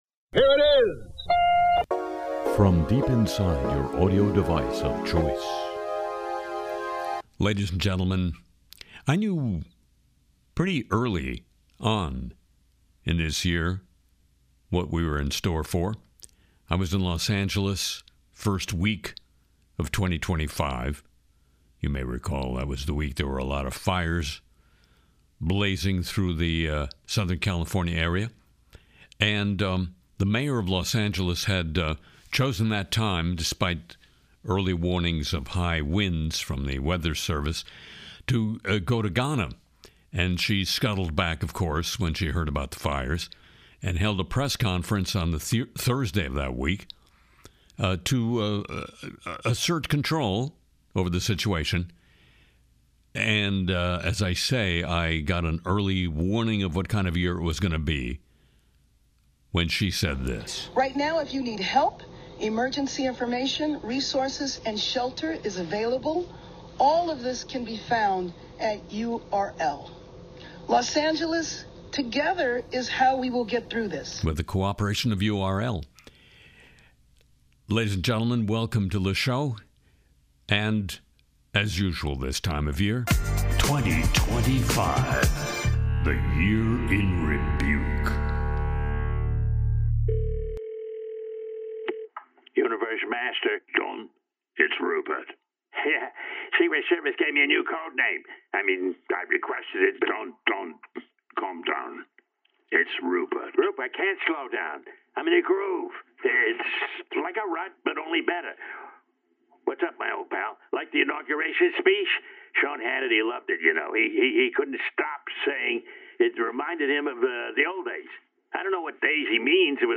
The final Le Show of 2025 looks back with “Year in Rebuke,” featuring the year’s sharpest satire, original songs, Trump mockery, and unforgettable news moments.